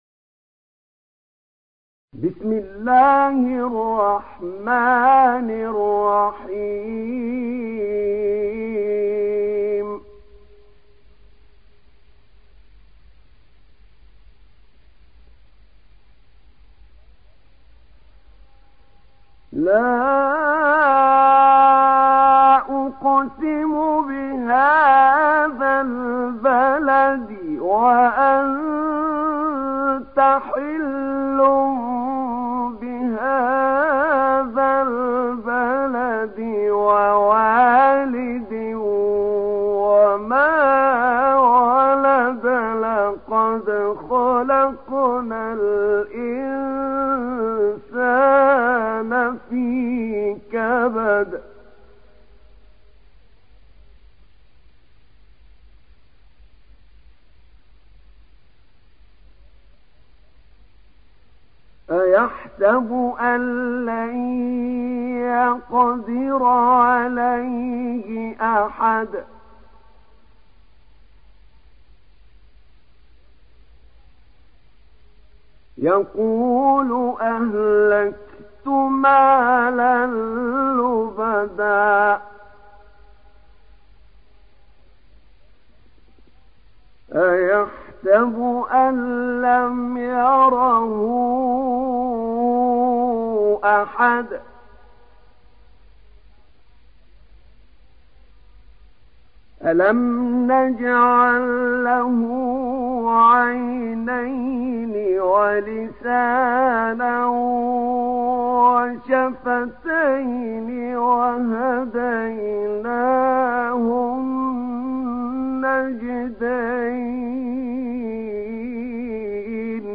تحميل سورة البلد mp3 بصوت أحمد نعينع برواية حفص عن عاصم, تحميل استماع القرآن الكريم على الجوال mp3 كاملا بروابط مباشرة وسريعة